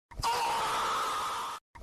Memes
Screaming Crying Emoji Dies